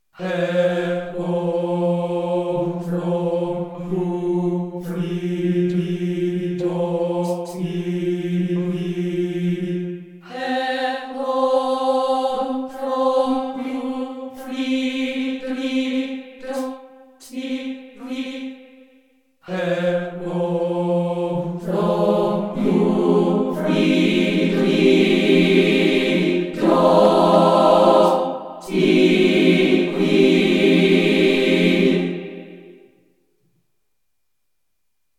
Choir sings